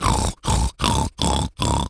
pgs/Assets/Audio/Animal_Impersonations/pig_sniff_deep_04.wav at master
pig_sniff_deep_04.wav